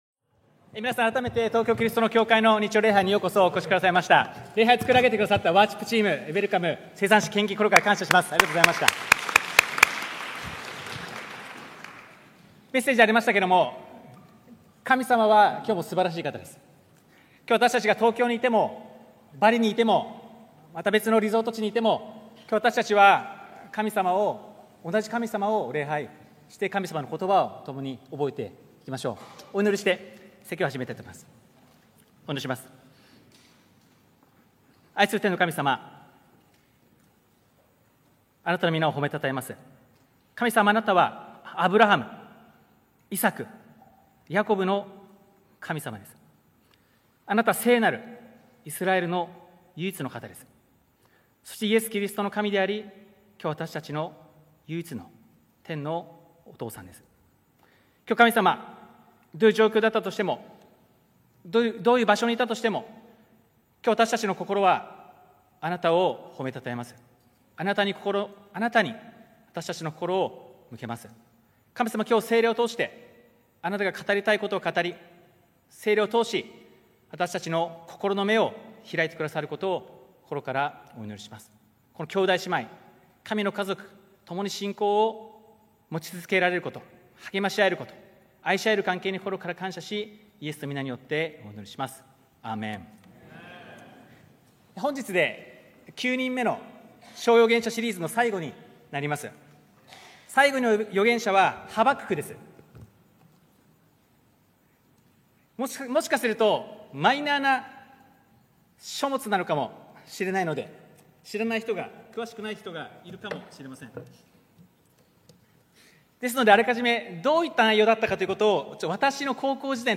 日曜礼拝説教「神様にできないことは、一つもない